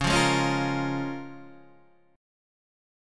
Db6 chord